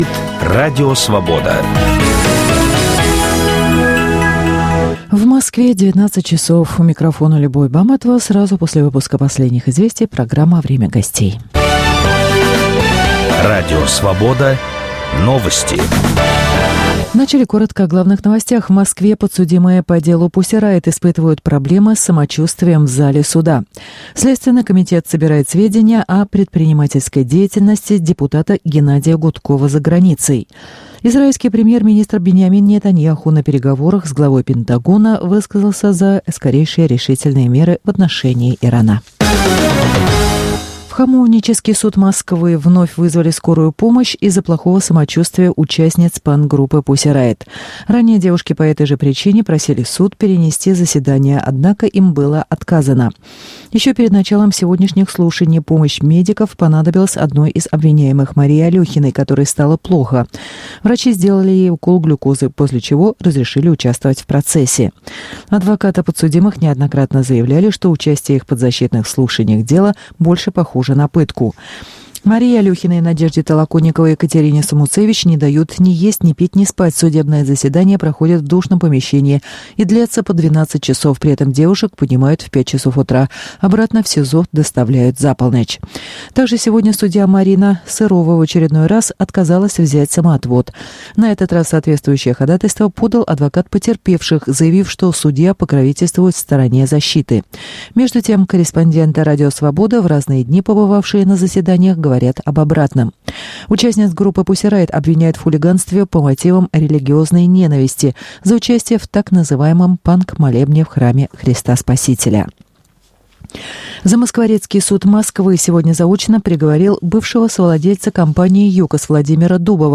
Что может оппозиция предложить украинскому обществу? В программе участвует кандидат в депутаты парламента от Объединенной оппозиции Александра Кужель.